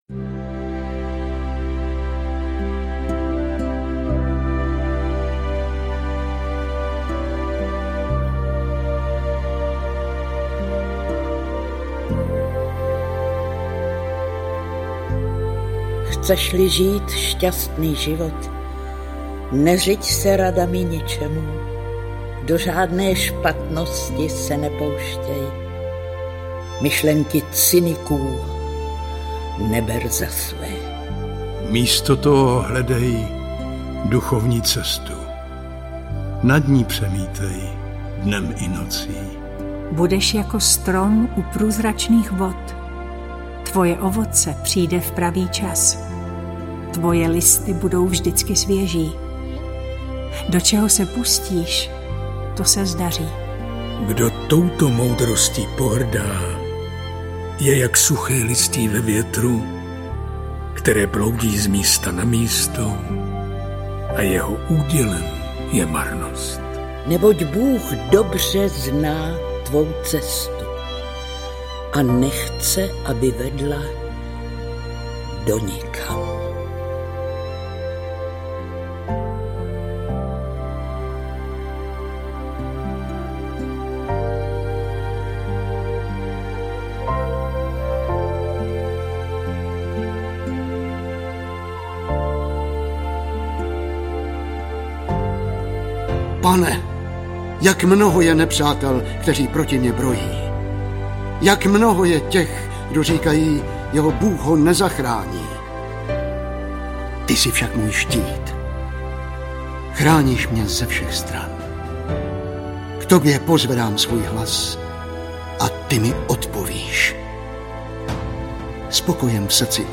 Audionahrávka přebásněných biblických žalmů představuje vyvrcholení projektu Biblické poezie. Všichni herci, kteří vystupovali na jednotlivých CD, se sejdou právě zde. Výsledkem je mimořádně barvitá interpretace široké škály pocitů, popsaných v žalmech.
Ukázka – Žalmy